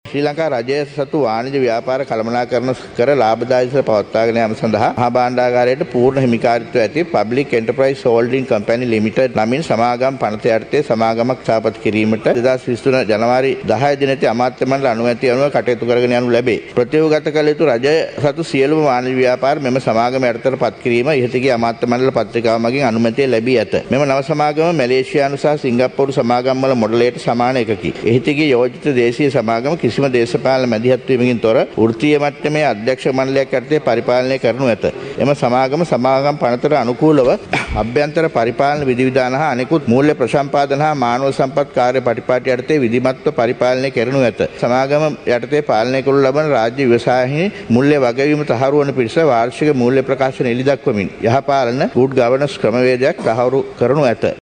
මේ අතර මුදල් රාජ්‍ය අමාත්‍ය රංජිත් සියඹලාපිටිය මහතා අද දින පාර්ලිමේන්තුවේදී පාඩු ලබන රාජ්‍ය ආයතන පෞද්ගලිකරණය කිරීම පිළිබදව මෙලෙස අදහස් දක්වා සිටියා.